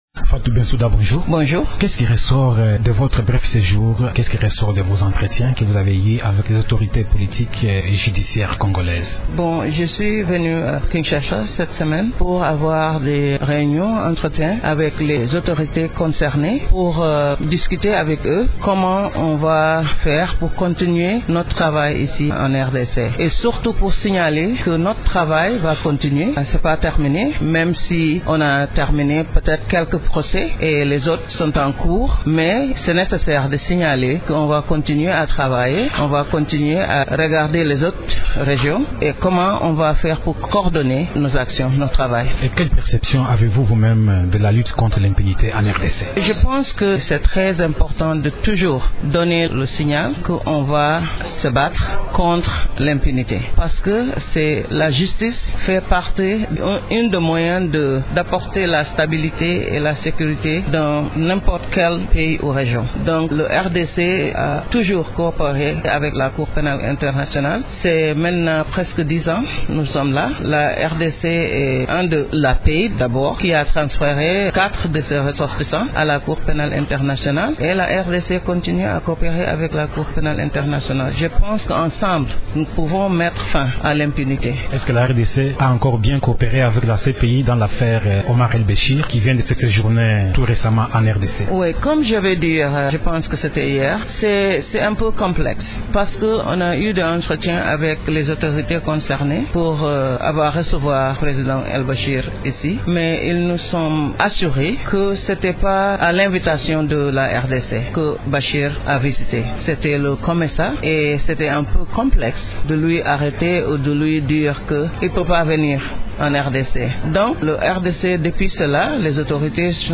Invitée de Radio Okapi ce matin, Fatou Bensouda a exprimé la volonté de la cour de travailler avec le gouvernement congolais pour combattre l’impunité en RDC.